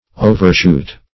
Overshoot \O`ver*shoot"\, v. i.